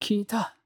戦闘 ダメージ ボイス 声素材 – Damage Voice